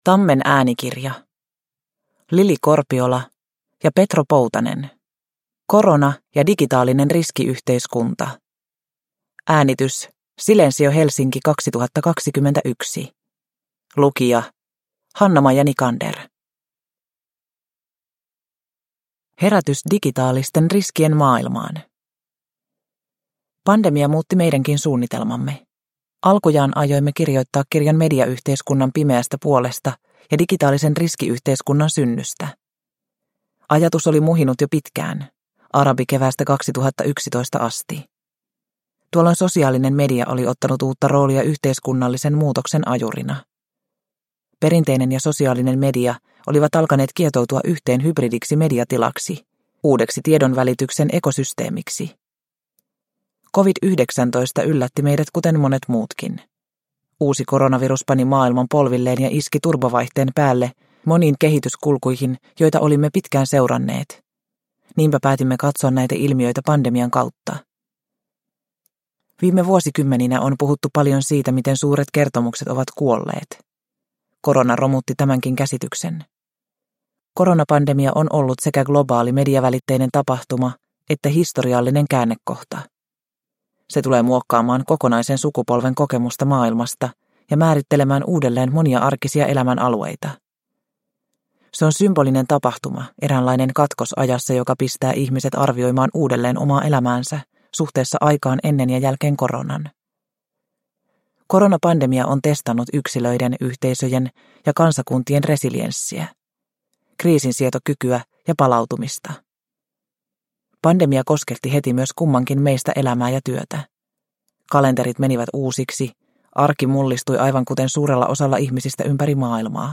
Korona – Ljudbok – Laddas ner